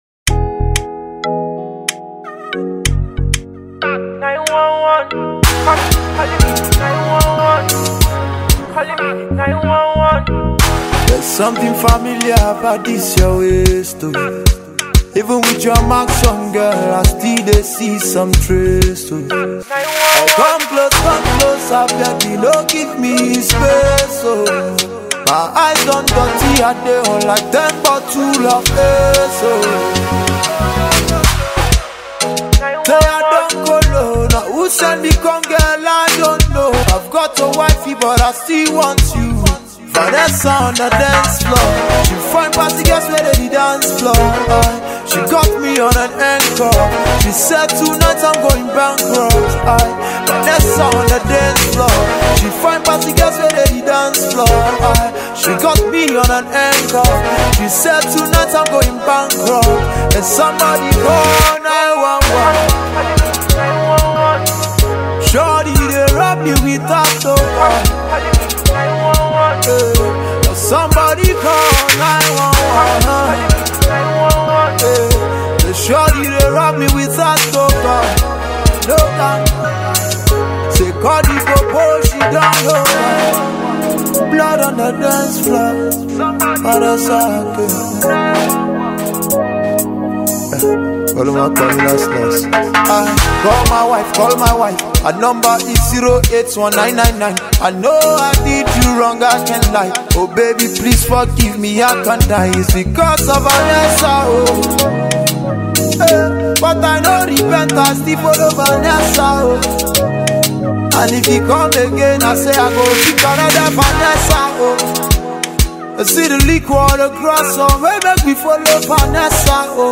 Nigerian Music